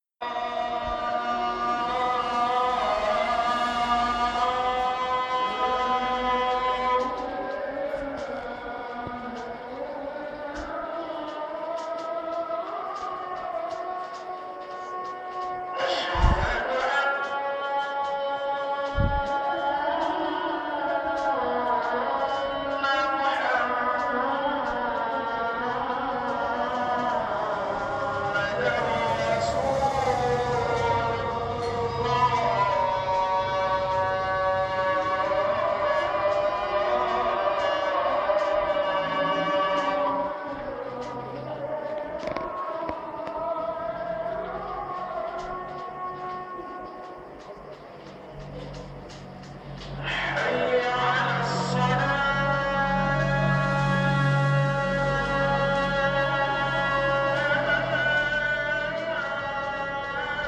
Мелодичный звук мечети в Индонезии